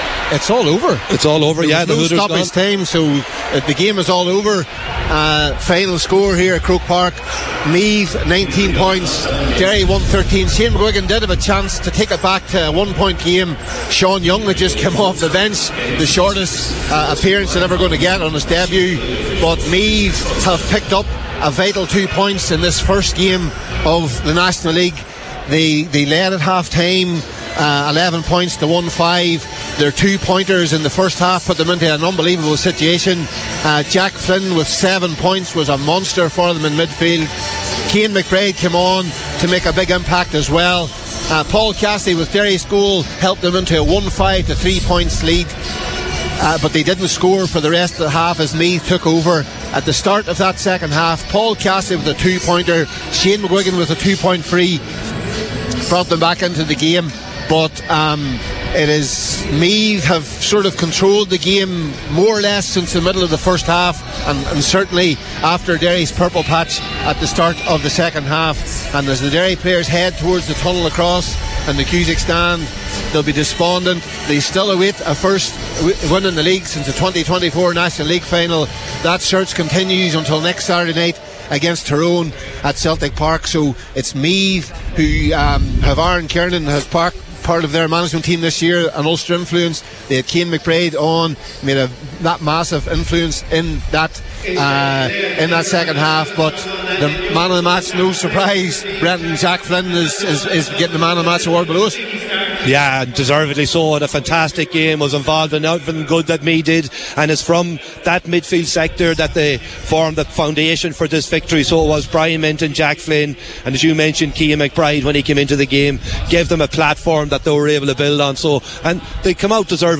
live at full time for Highland Radio Saturday Sport…